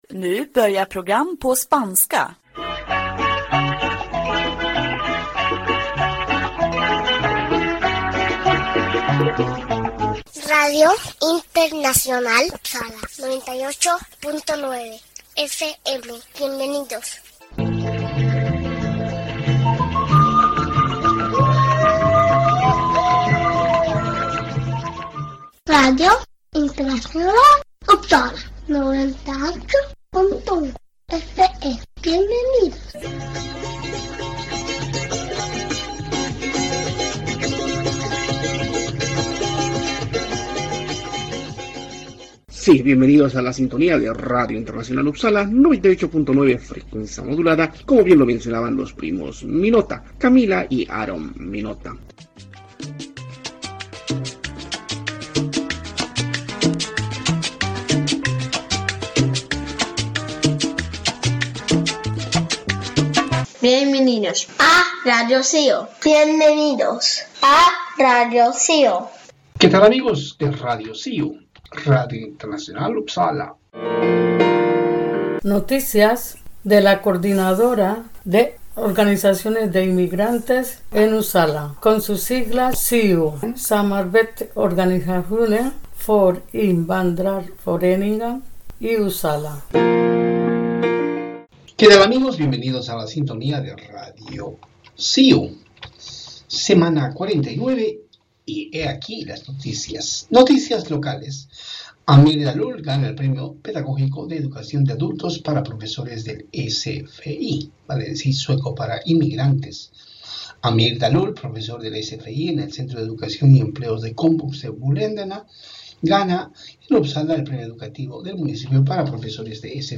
Sí, radio de cercanías en Uppsala se emite domingo a domingo a horas 18:30. La asociación de amistad Suecia-Latinoamérica SANKHAYU está detrás de todo este trabajo informativo,que fundamentalmente enfoca a noticias que tienen que ver con el acontecer de los inmigrantes en Uppsala.